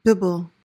PRONUNCIATION: (BIB-l) MEANING: verb tr.:1.